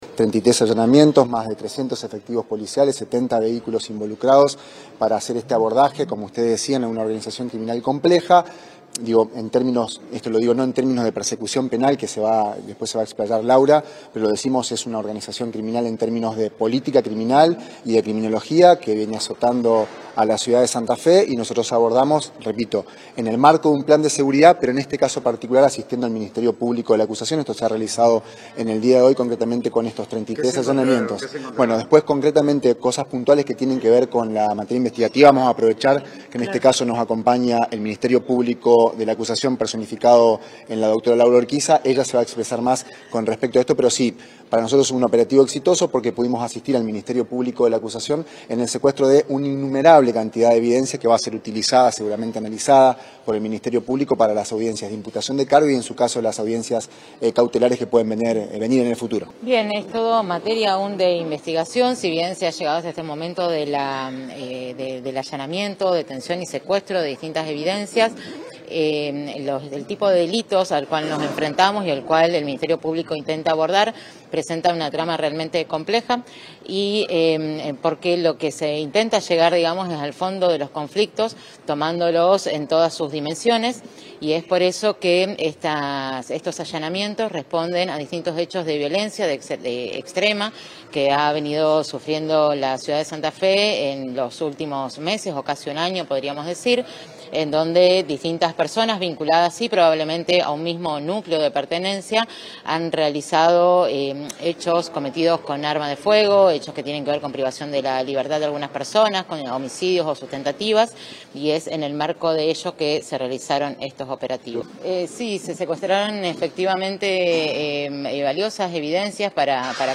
El director provincial de Investigación Criminal, Rolando Galfrascoli, junto a la fiscal María Laura Urquiza, brindaron detalles del operativo, que se desplegó en distintos puntos de la ciudad -entre ellos los barrios Los Jesuitas, Loyola Norte, Loyola Sur, La Loma, San Agustín, Coronel Dorrego y Candioti Norte- y también en la localidad de Nelson.
Declaraciones de Galfrascoli y Urquiza